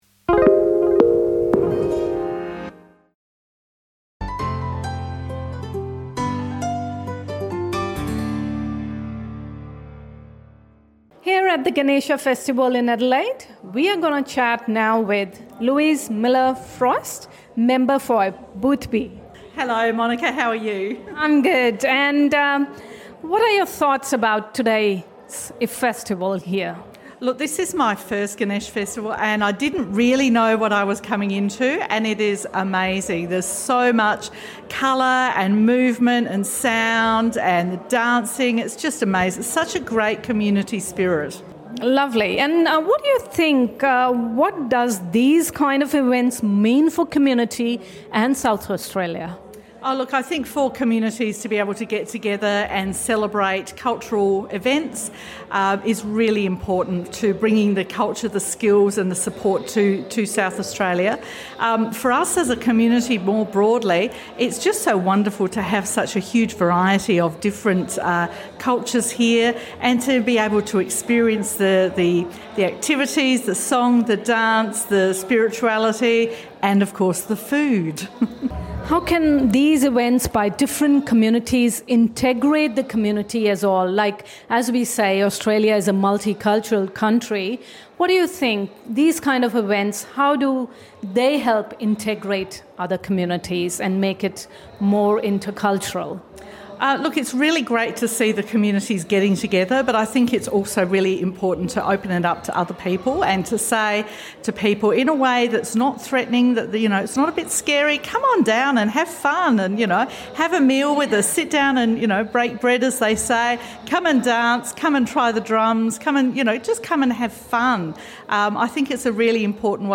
Members of Parliament and dignitaries joined the Indian community in Adelaide to celebrate Ganesh Chaturthi at the United Indians of South Australia's (UIOSA) Arts and Cultural Festival. Adorning colourful Indian clothes here's what some of them had to say about the festival.